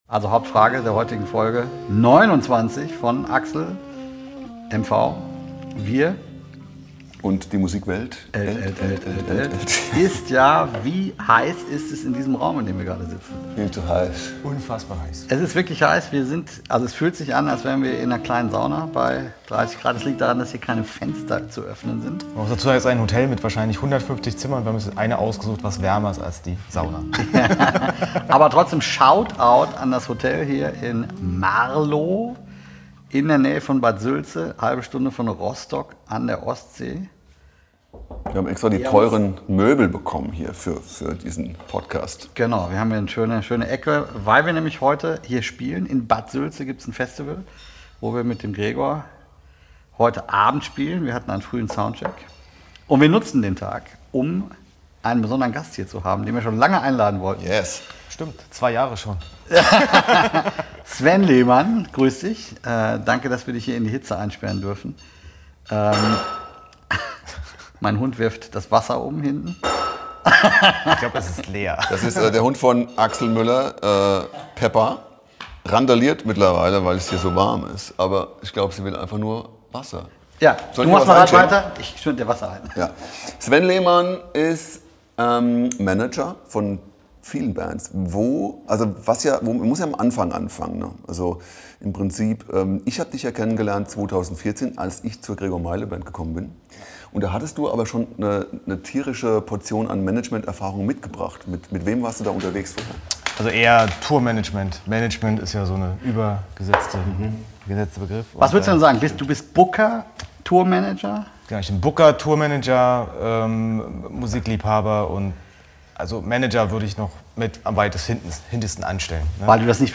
Der Gigmacher: Booker & Tourmanager